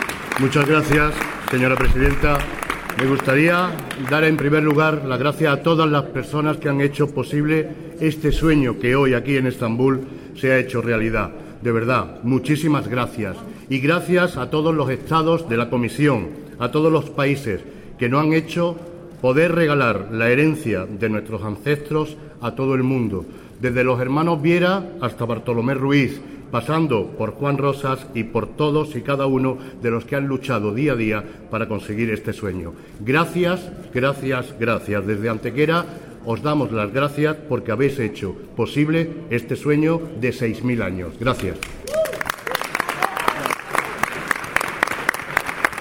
Discurso y declaraciones del Alcalde de Antequera
Audio con el discurso de agradecimiento del alcalde de Antequera, Manolo Barón, pronunciado durante la UNESCO World Heritage Committee en el que ha sido declarado como Patrimonio de la Humanidad el Sitio Dólmenes de Antequera. También incluyo las primeras declaraciones realizadas tras la consecución de la proclamación.